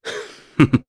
Nicx-Vox_Happy1_jp.wav